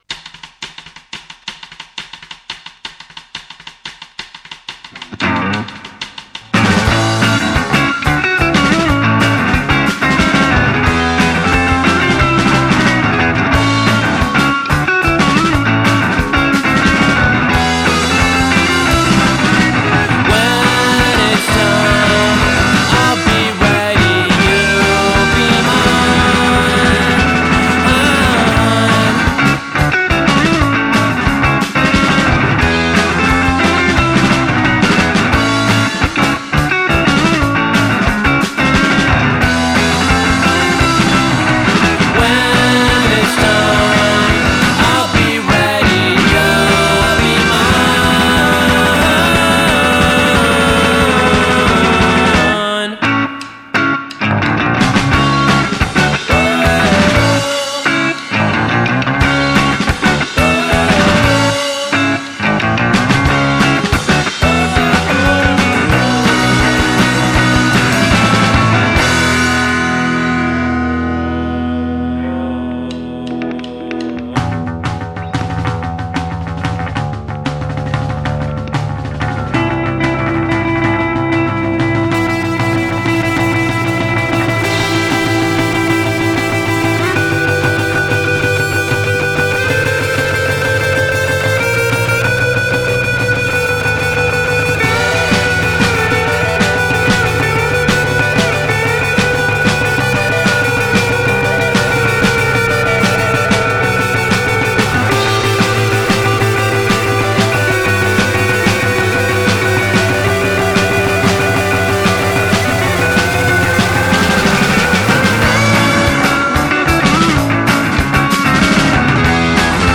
three piece noise-pop band